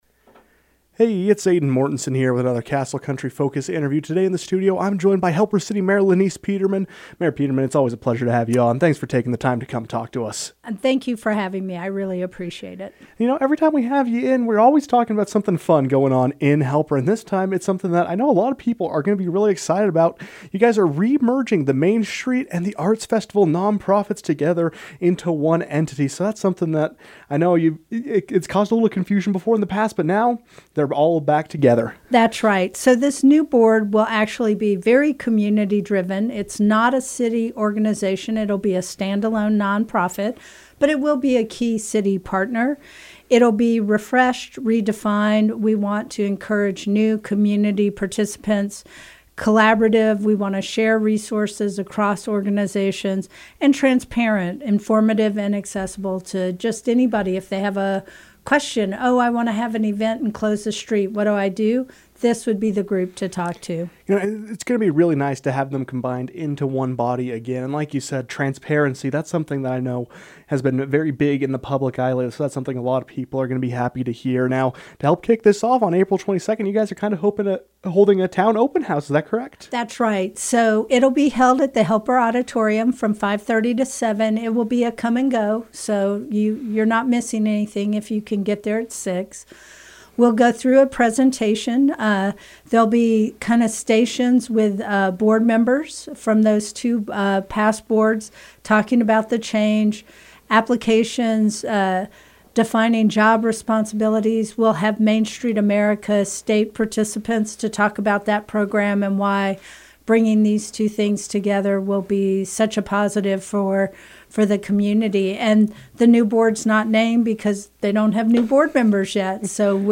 The City will soon see the merging of two nonprofit organizations – the Arts Festival and Main Street organizations – which will combine into a single coalition. Helper City Mayor Lenise Peterman joined the KOAL newsroom to discuss this merger and other Helper happenings.